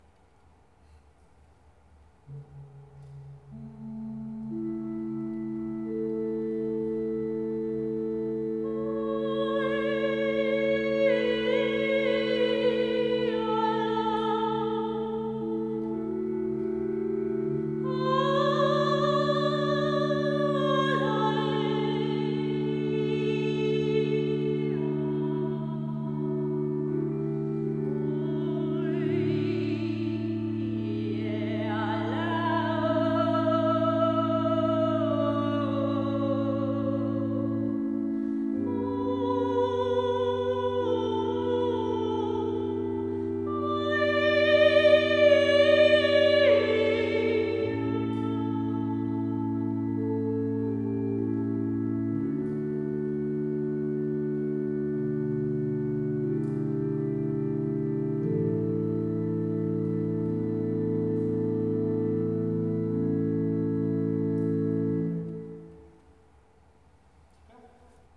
CHANTRE
Interlude intuitif – Orgue